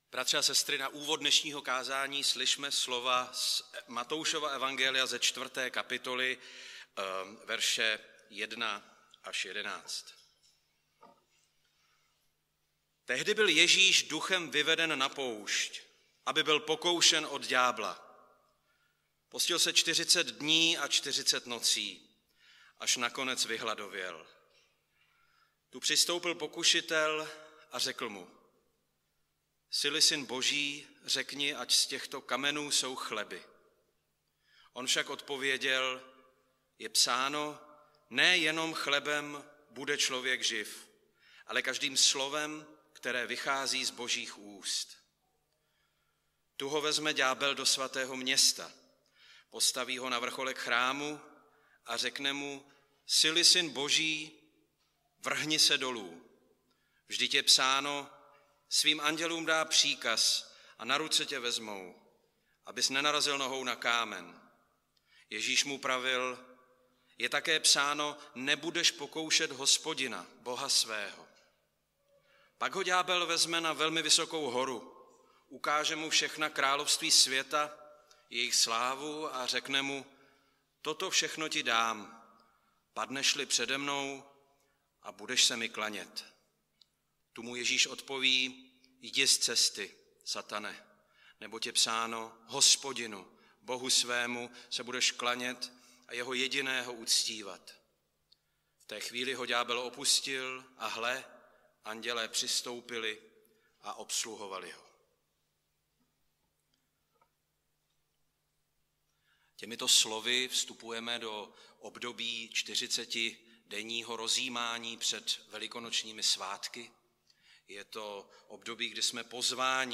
Nedělní kázání – 13.3.2022 Synovská pokušení